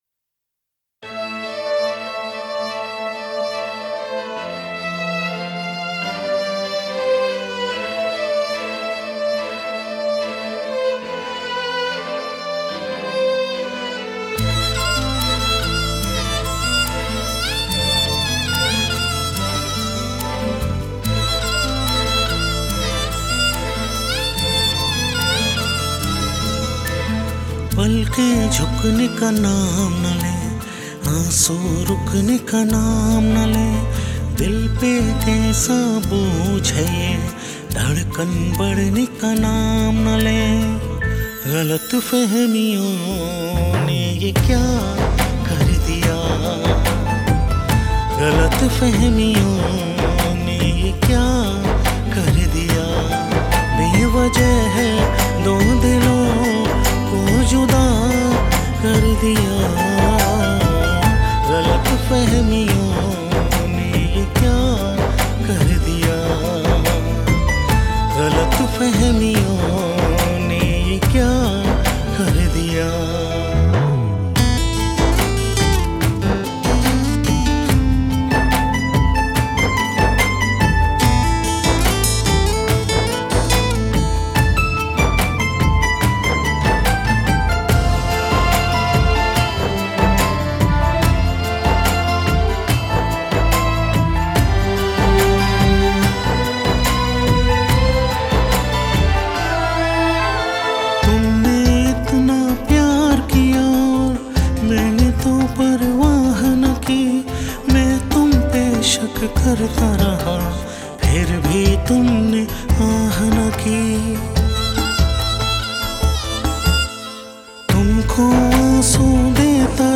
Bollywood Mp3 Music 2019